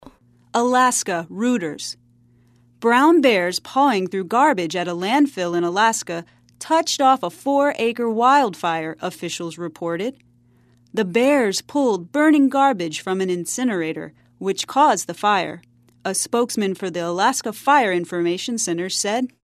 在线英语听力室赖世雄英语新闻听力通 第1期:棕熊造成阿拉斯加大火的听力文件下载,本栏目网络全球各类趣味新闻，并为大家提供原声朗读与对应双语字幕，篇幅虽然精短，词汇量却足够丰富，是各层次英语学习者学习实用听力、口语的精品资源。